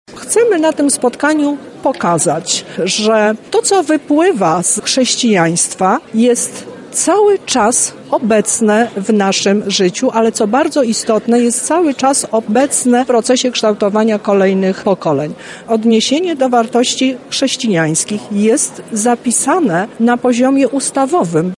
Dzisiaj (14.04) odbyła się konferencja pod nazwą „Ukształtowało nas chrześcijaństwo” w ramach obchodów Święta Chrztu Polski z udziałem Lubelskiej Kurator Oświaty oraz Wojewody Lubelskiego.
Teresa Misiuk-mówi Teresa Misiuk, Lubelska Kuratorka Oświaty.